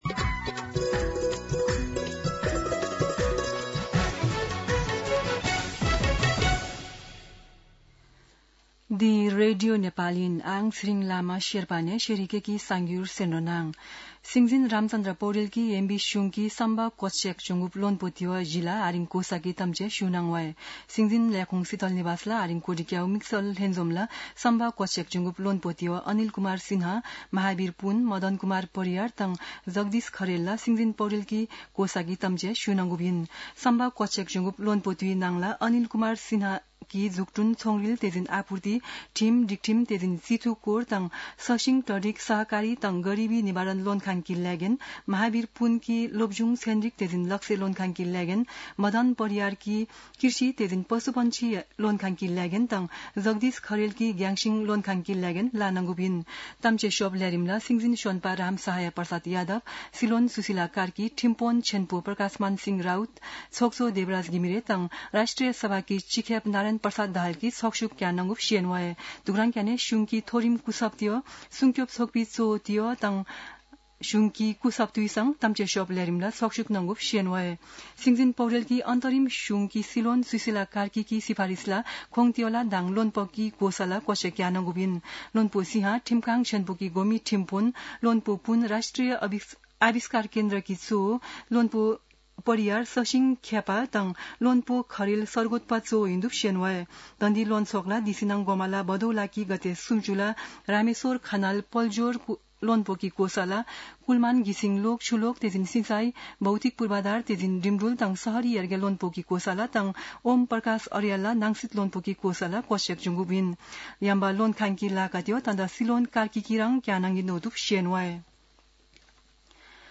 शेर्पा भाषाको समाचार : ६ असोज , २०८२
Sherpa-News-9.mp3